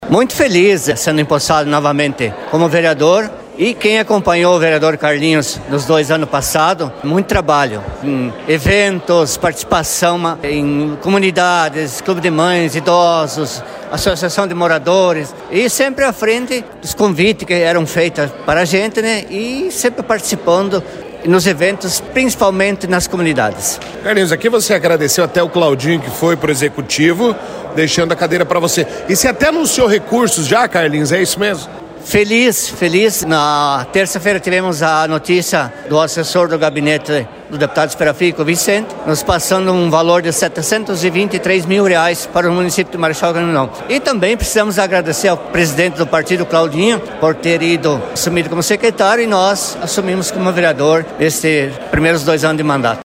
Carlinhos Silva fala sobre sua posse e já anuncia recursos conquistados de mais de 700 mil reais, atraves do deputado federal Dilceu Sperafico……..Ouça áudio